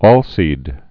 (ôlsēd)